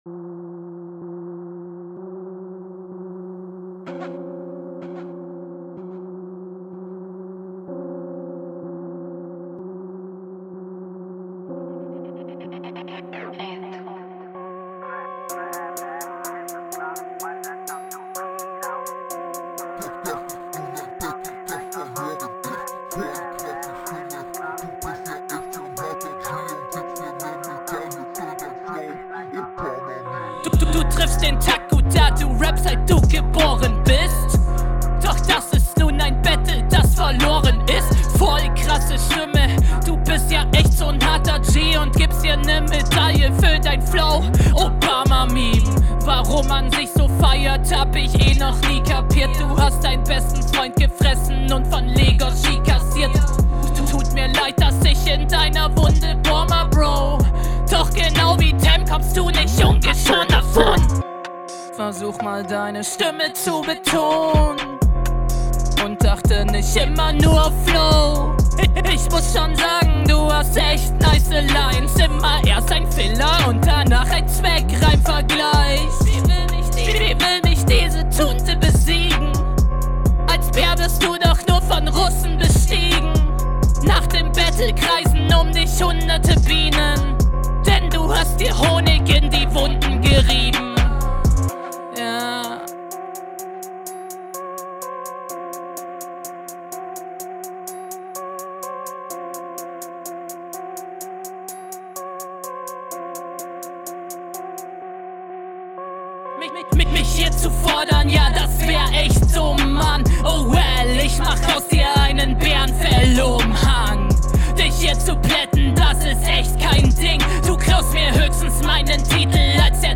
Du hast eine echt nice Pausensetzung für den Beat.
Flow: Finde ich absolut auf dem Beat nicht schlecht.